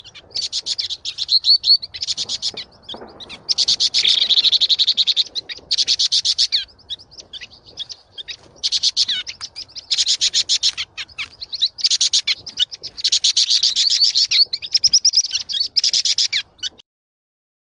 棕头鸦雀叫声